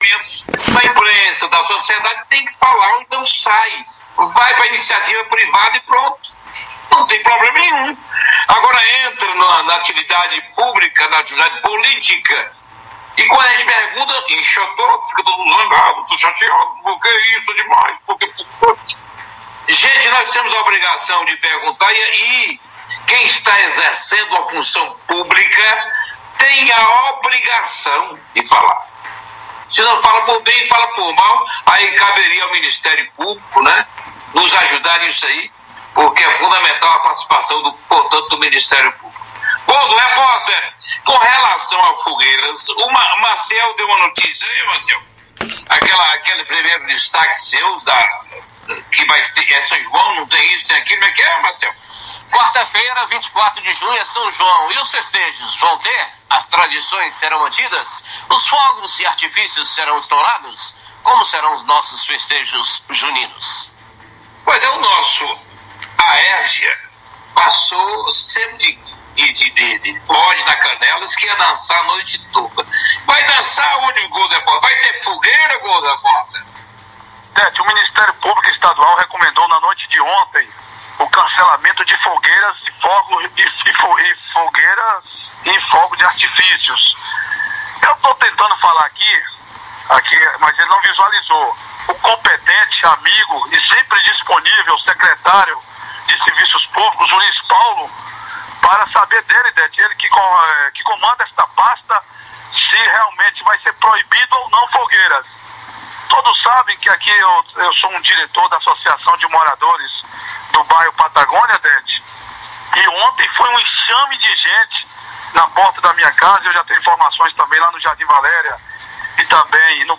Em reportagem exibida no programa Redação Brasil de hoje (segunda-feira) os comunicadores